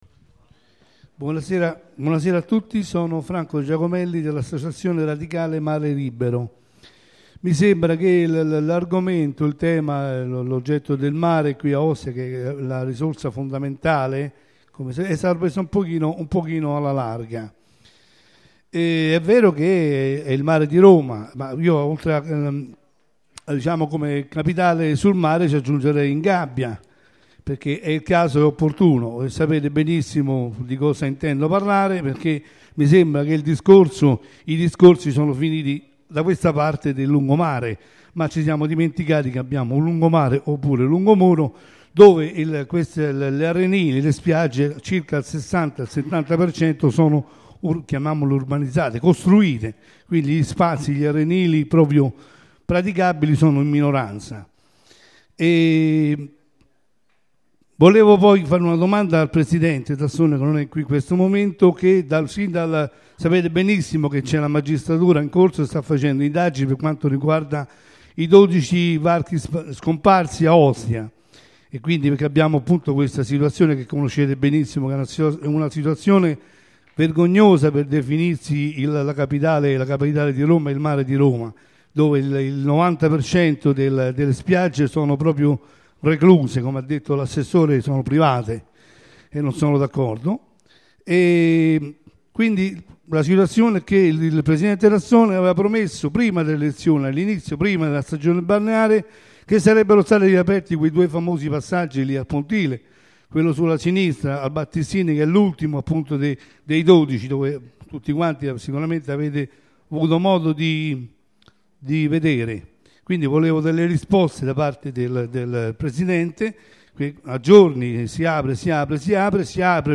Registrazione integrale dell'incontro svoltosi il 9 giugno 2014 presso il Teatro del Lido in Via delle Sirene, 22